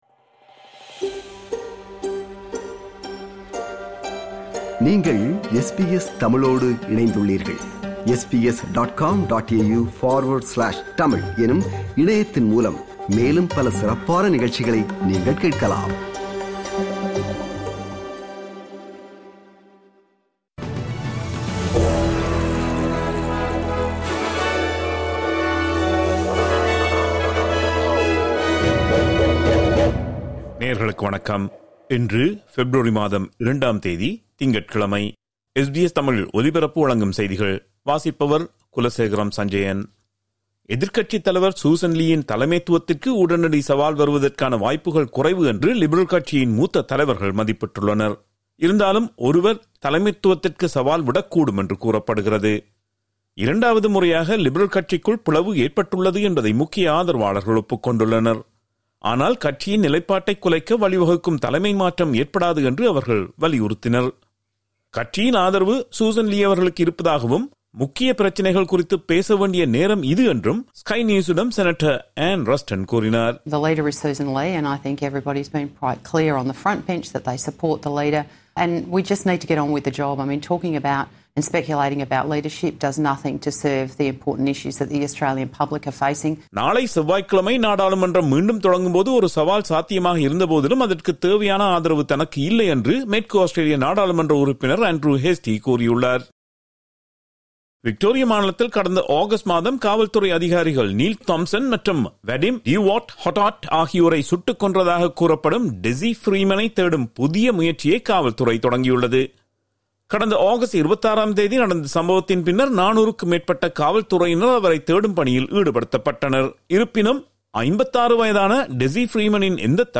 SBS தமிழ் ஒலிபரப்பின் இன்றைய (திங்கட்கிழமை 02/02/2026) செய்திகள்.